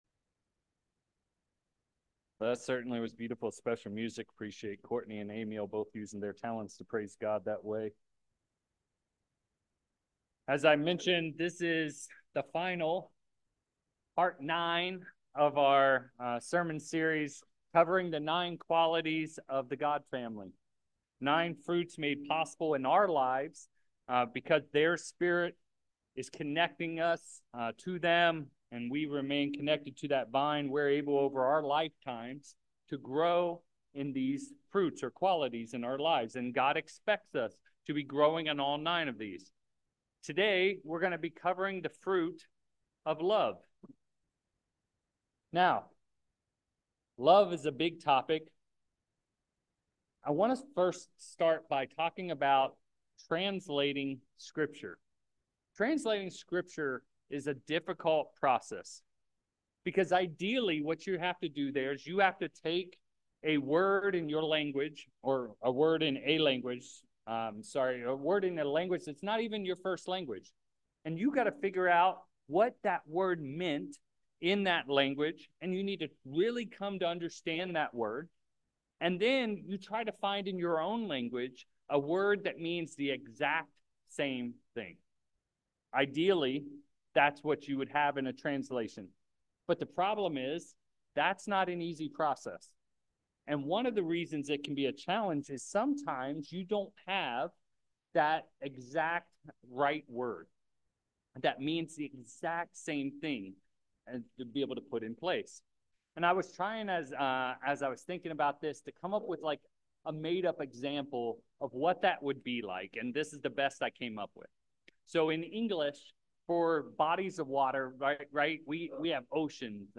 Given in Petaluma, CA San Francisco Bay Area, CA